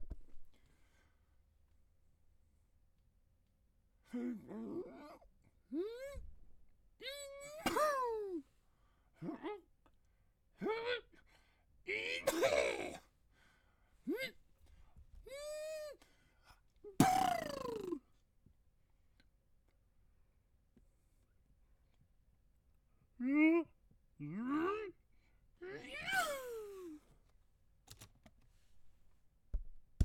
Cartoon_Dragon_Spits
cartoon choke cough exhale funny goofy ill male sound effect free sound royalty free Memes